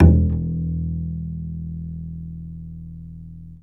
DBL BASS F#1.wav